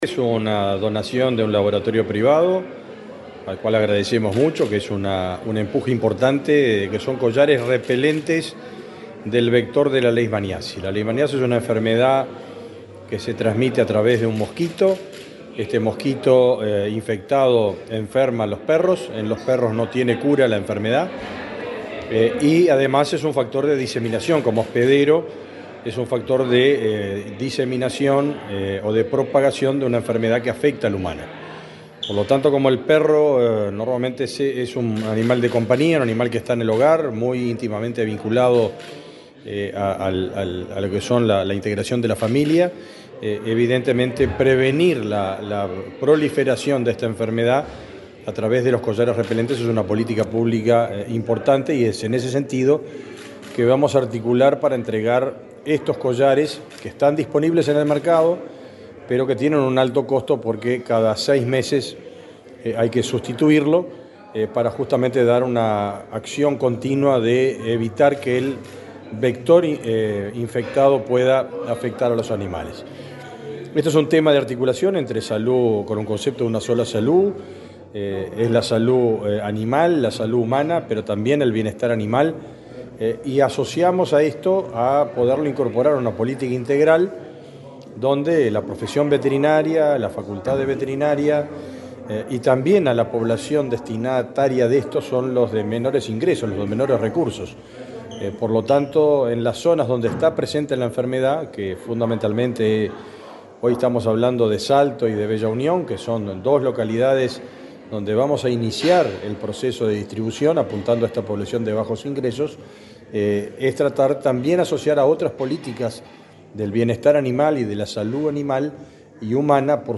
Declaraciones del ministro de Ganadería, Fernando Mattos
El Ministerio de Ganadería, Agricultura y Pesca (MGAP), el Instituto Nacional de Bienestar Animal, la Comisión Nacional Honoraria de Zoonosis y la Fundación Marco Podestá firmaron un acuerdo en Salto, a fin de definir la estrategia para colocar más de 30.000 collares en canes a efectos de combatir la leishmaniasis. El titular del MGAP, Fernando Mattos, explicó a la prensa el alcance de la medida.